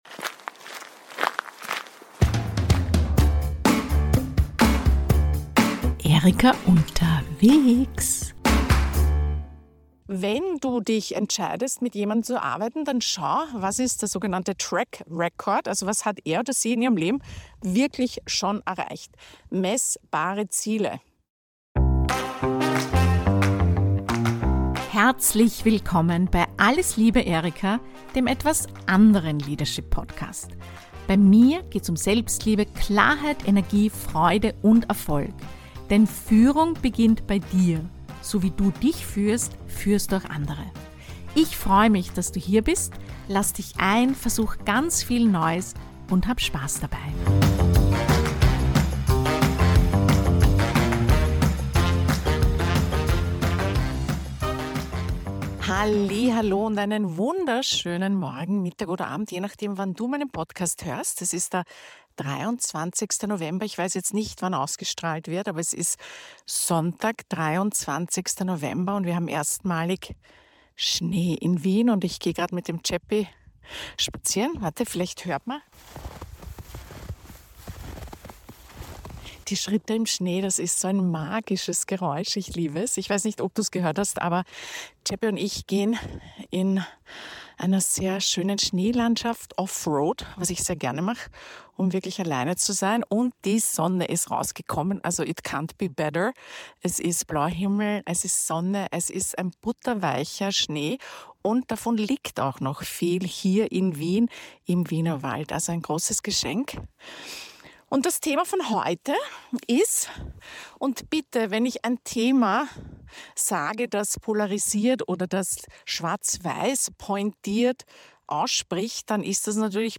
Live aus dem verschneiten Wienerwald